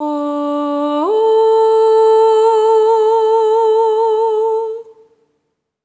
SOP5TH D4 -L.wav